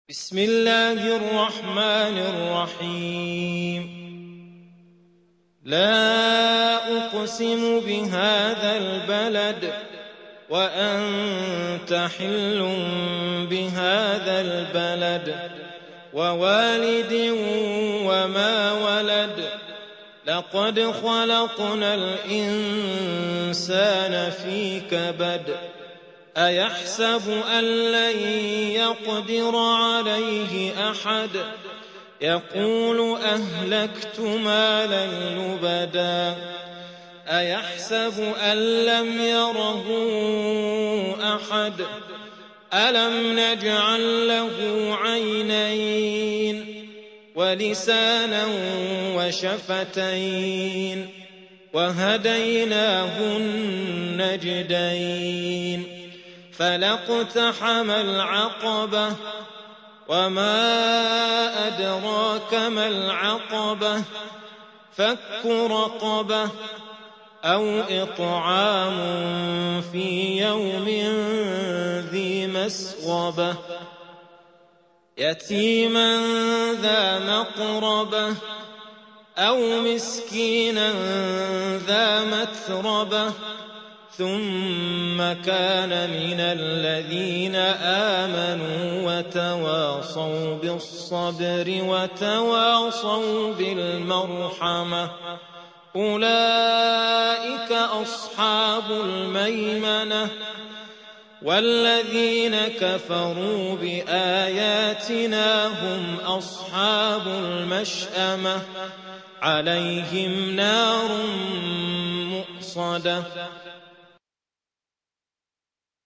90. سورة البلد / القارئ